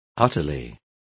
Complete with pronunciation of the translation of utterly.